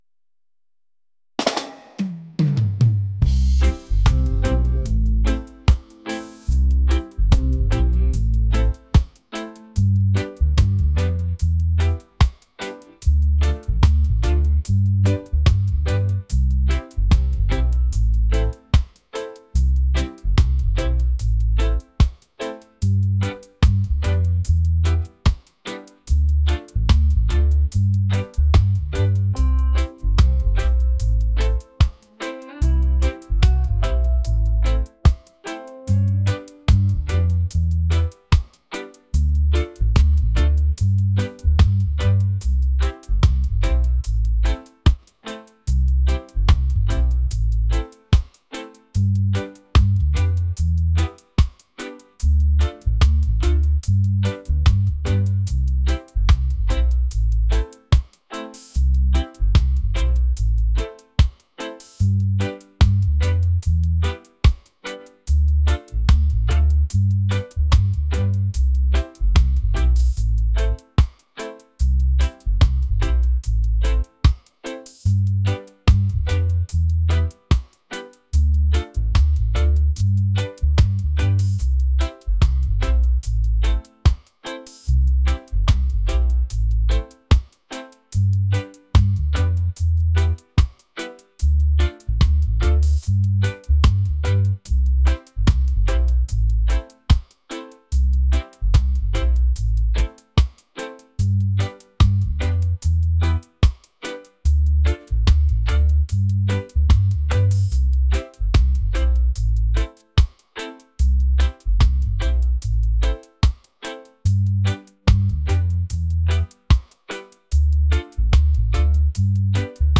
smooth | romantic | reggae